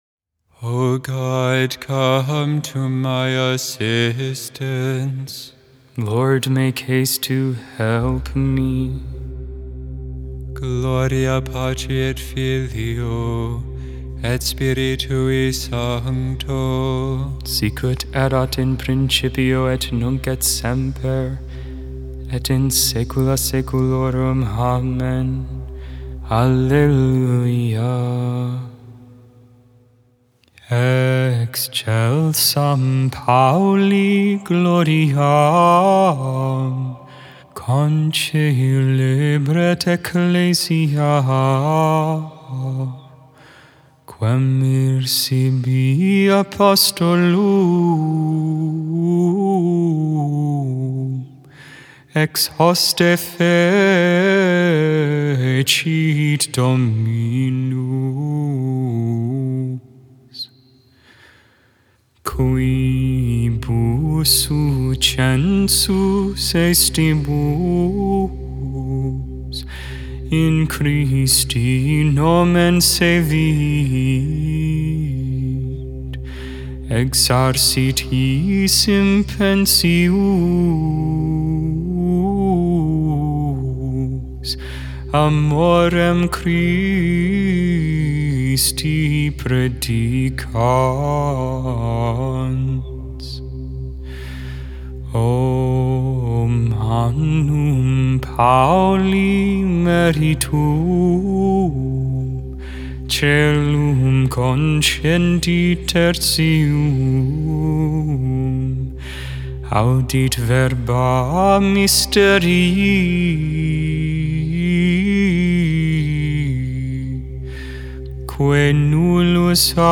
Psalm 126 (tone 2)